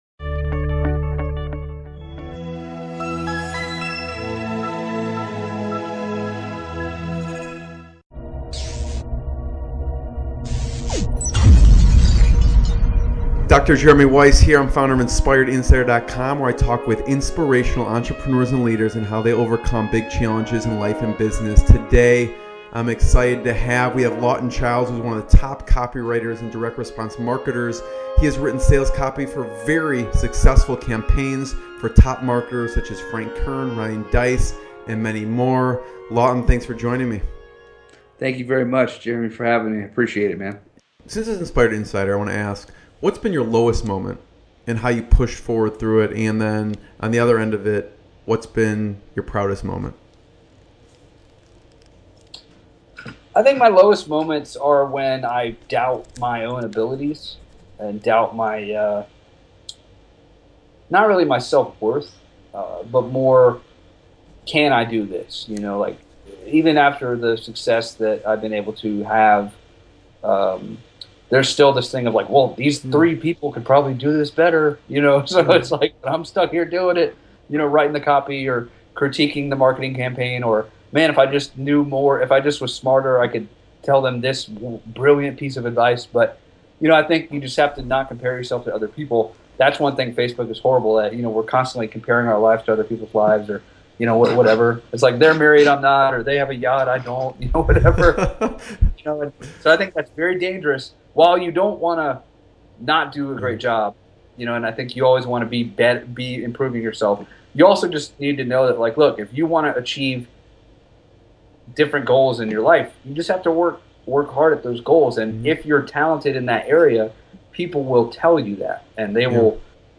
Inspirational Business Interviews